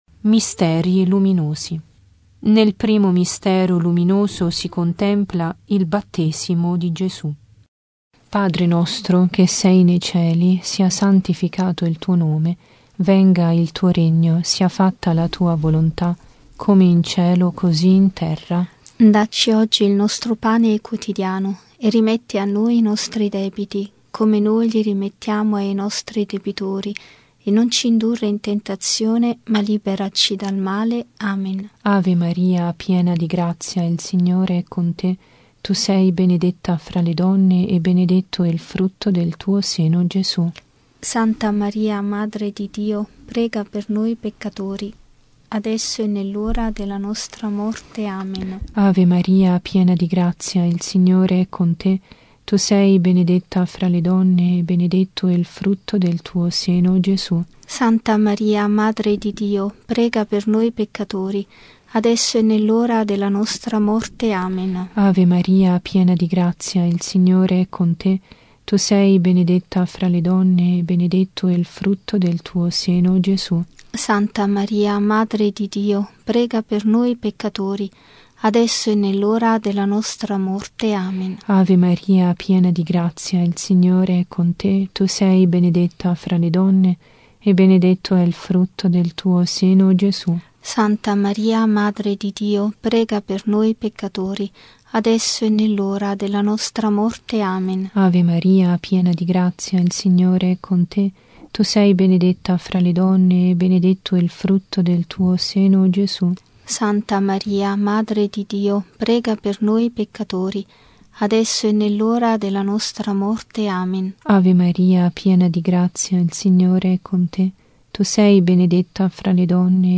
Genere: Rosario.